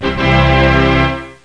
1 channel
mm_tada.mp3